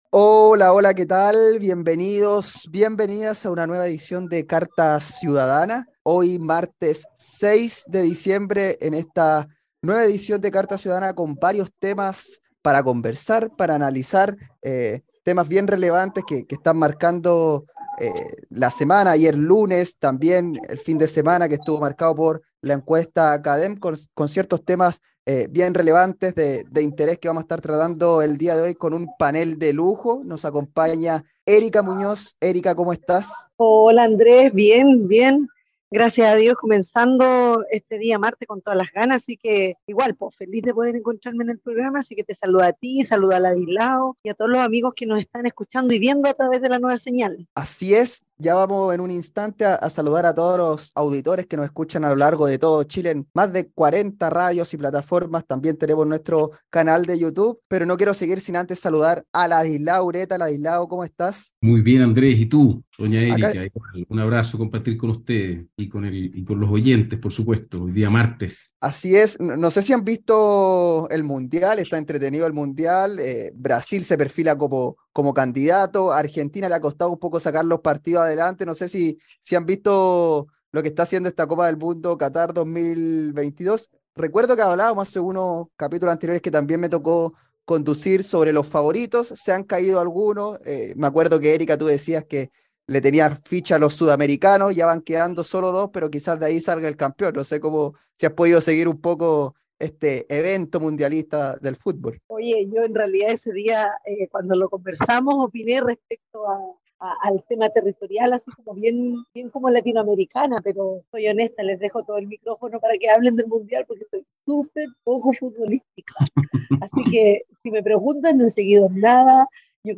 programa de conversación y análisis de la contingencia en Chile.